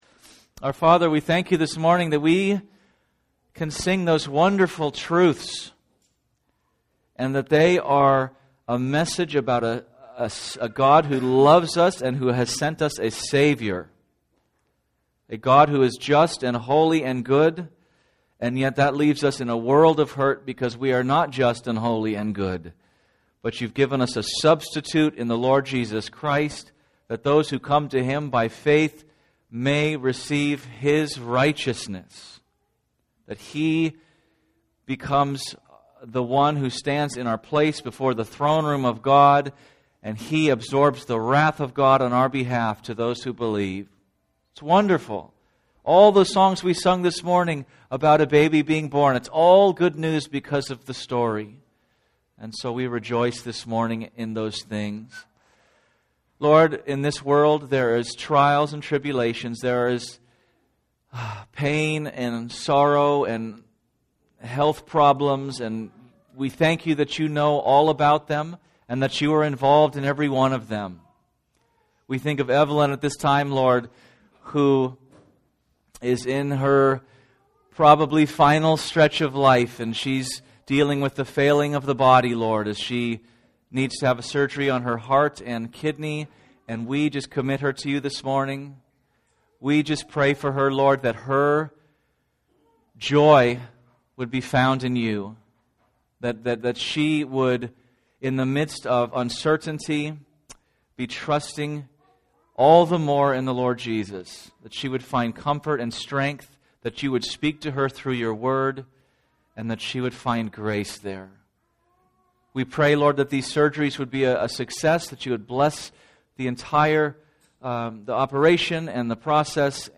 HVBC-Sermon-12-8-19.mp3